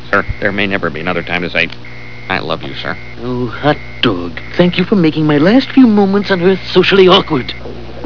A Young Smithers on the PA at the school gym.